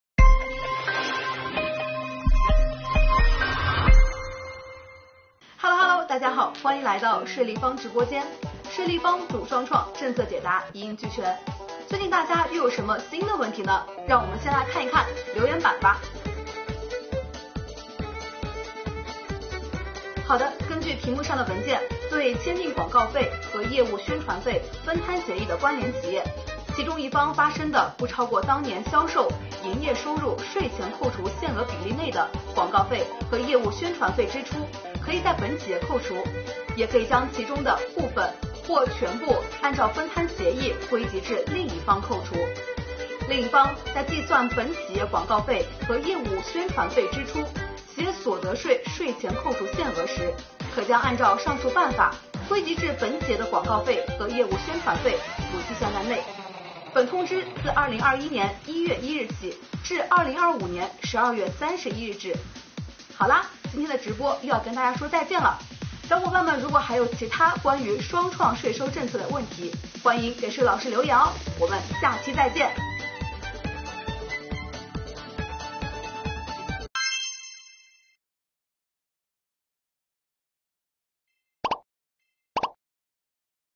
《税立方直播间》政策解答类系列视频又来啦！今天，税老师为您讲解：分摊协议下关联企业广告费和业务宣传费的涉税处理。